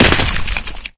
bodysplat.ogg